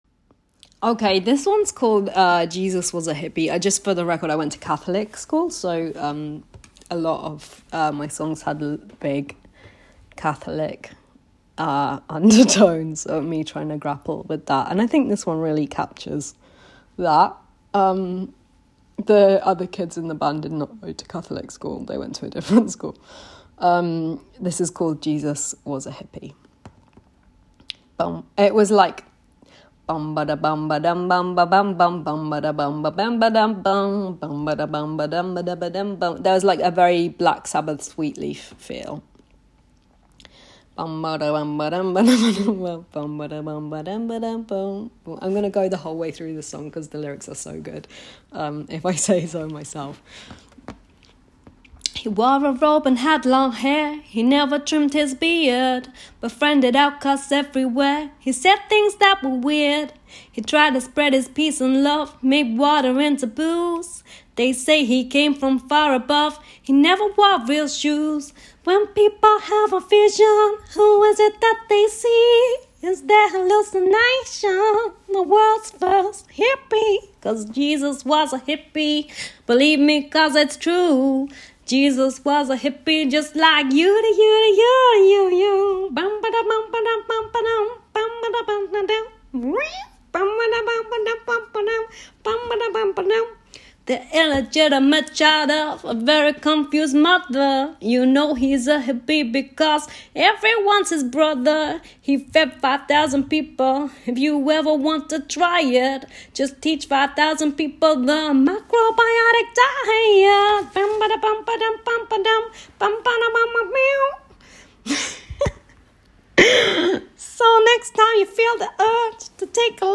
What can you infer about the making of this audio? in voice-note form